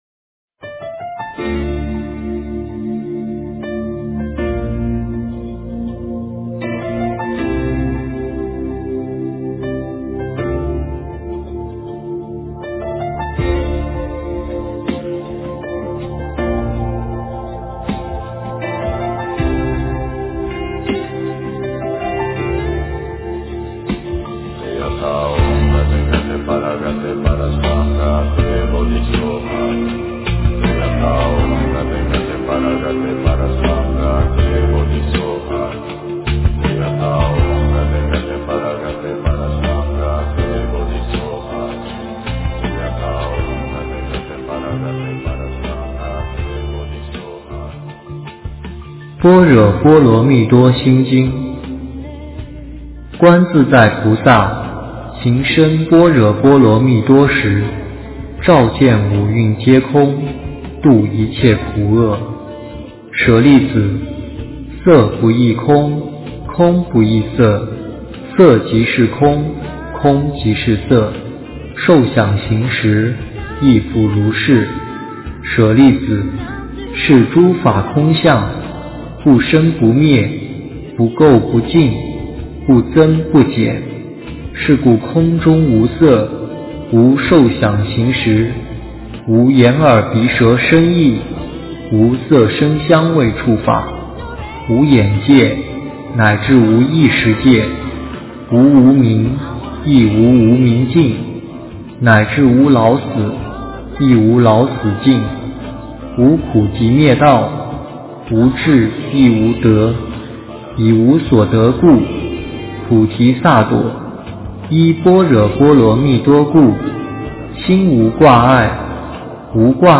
诵经
佛音 诵经 佛教音乐 返回列表 上一篇： 地藏菩萨的故事 下一篇： 大悲咒 相关文章 三稽首--如是我闻 三稽首--如是我闻...